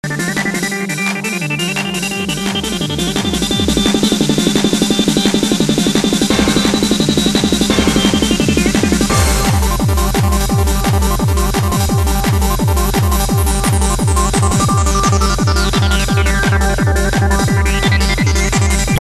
Dog Running help id this trance track
i am pretty sure it aint darude lol but it is an amazing track. so fast and shit. there are absolutely no vocals in this song. not that much bass but a lot of synth and percussion. i posed a sample of the song. plz help me id it!